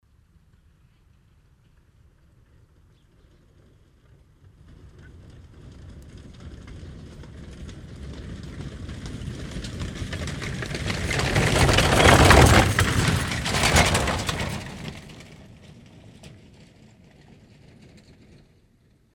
pferdekutsche.mp3